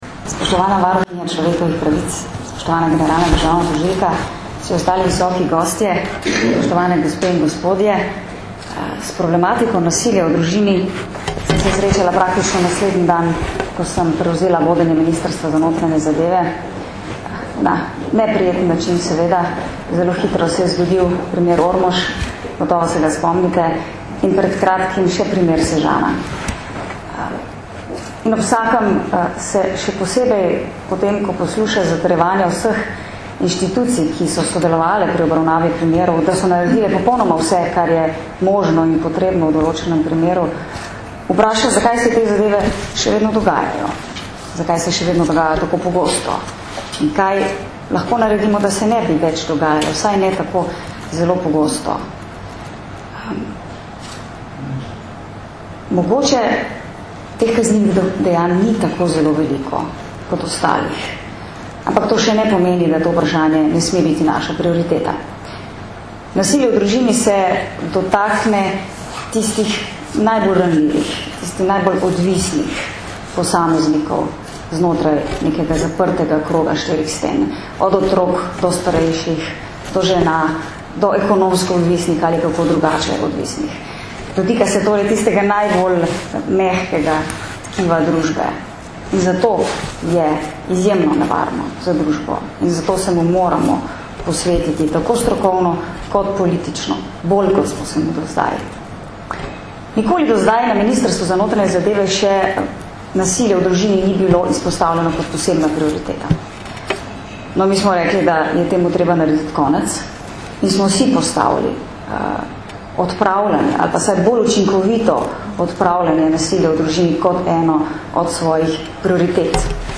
Na današnji otvoritvi so udeležence z uvodnimi besedami pozdravili v. d. generalnega direktorja policije Janko Goršek in ministrica za notranje zadeve Katarina Kresal, varuhinja človekovih pravic dr. Zdenka Čebašek Travnik, generalna državna tožilka Barbara Brezigar, državni sekretar na Ministrstvu za pravosodje Boštjan Škrlec in direktor Centra za izobraževanje v pravosodju Damijan Florjančič.
Govor ministrice za notranje zadeve Katarine Kresal (mp3)